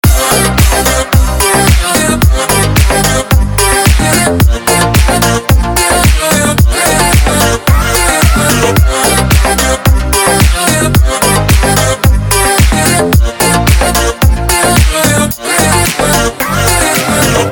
• Качество: 160, Stereo
dance
Electronic
без слов
Приятная танцевальная музыка без слов